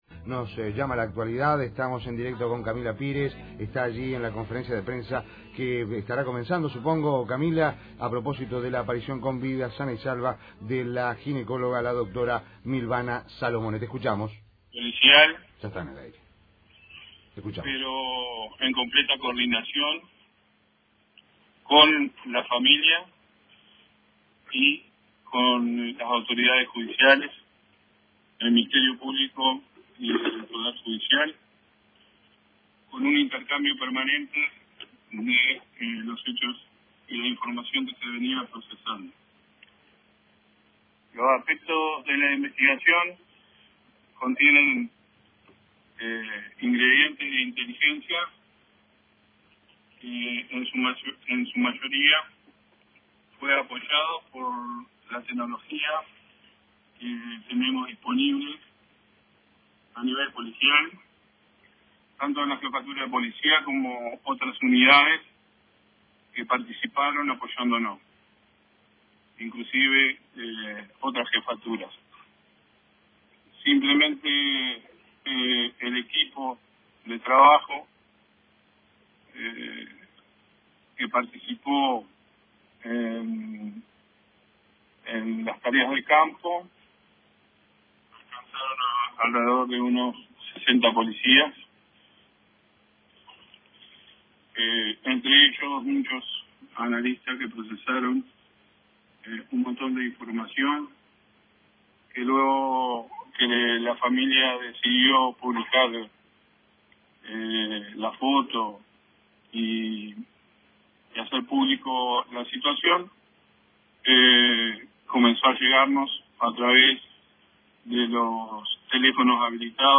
Mario Layera en conferencia de prensa